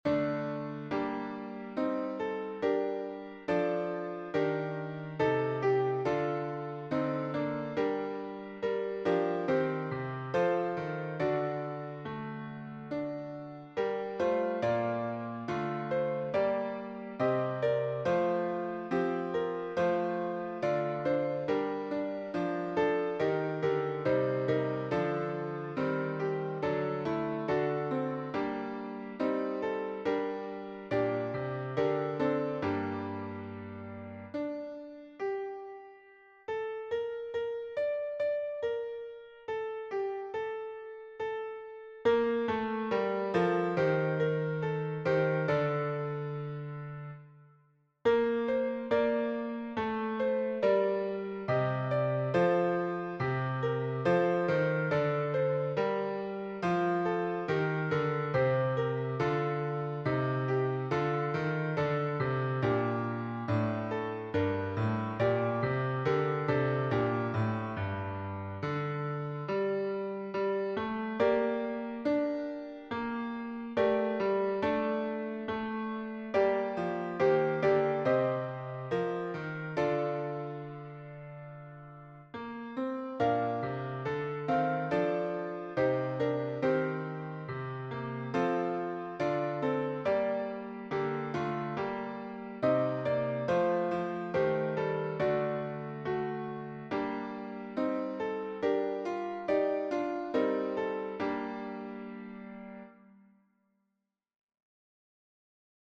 MP3 version piano